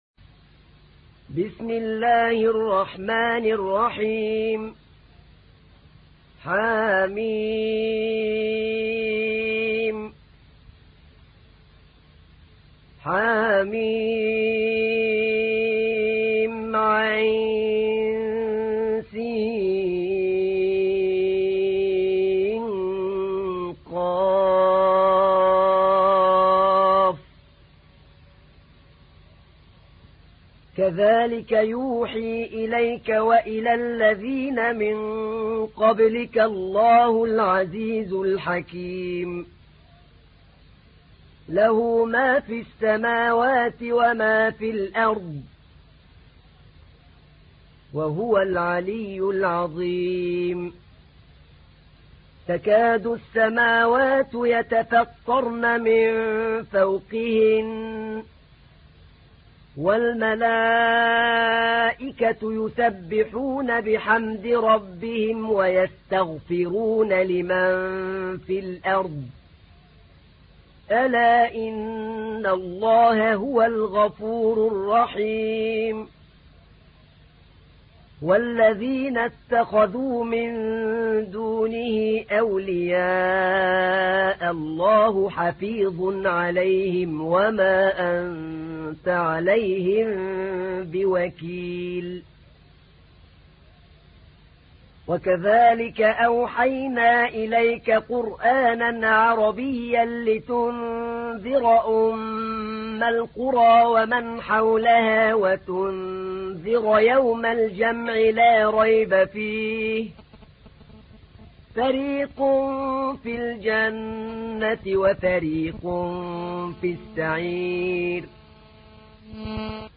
تحميل : 42. سورة الشورى / القارئ أحمد نعينع / القرآن الكريم / موقع يا حسين